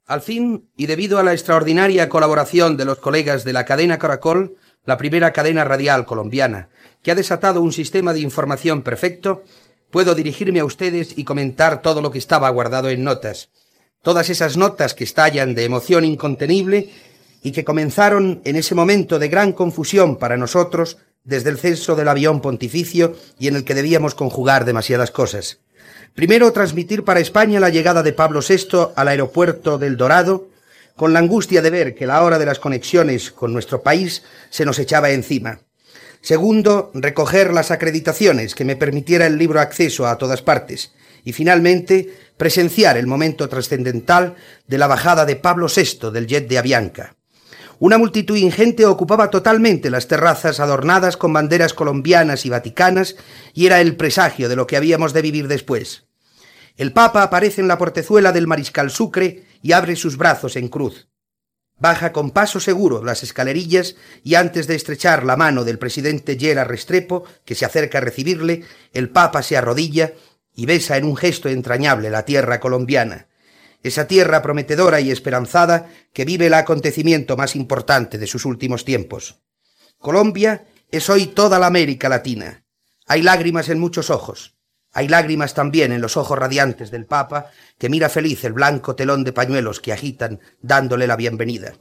Crònica del viatge del Sant Pare Pau VI a Bogotà